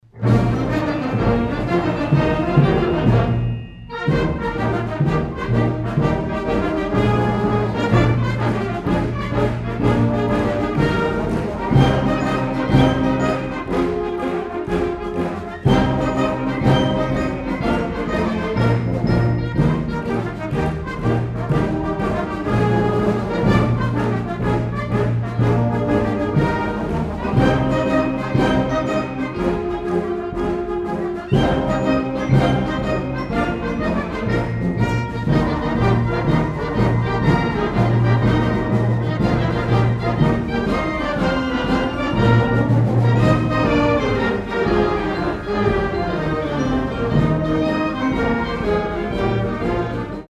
Below are music excerpts from some of our concerts.
2009 Winter Concert
December 20, 2009 - San Marcos High School